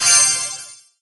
sfx_recovery_hp.mp3